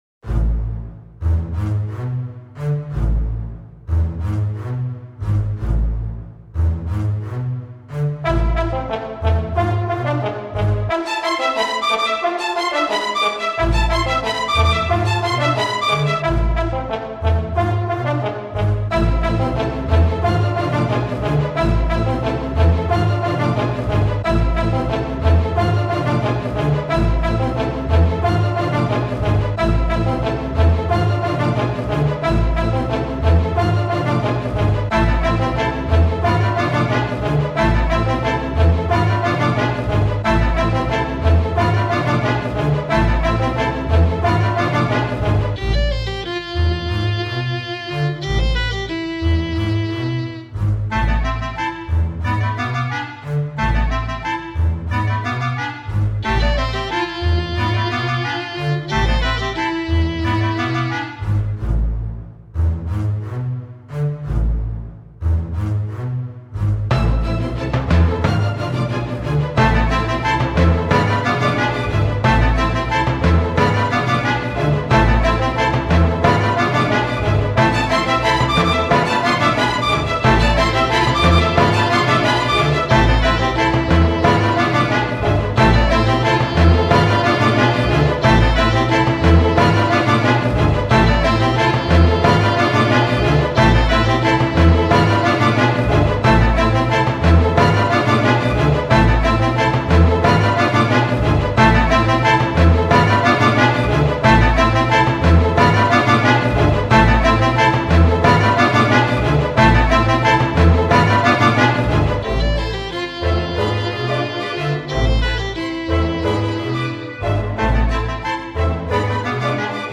Cinematic Score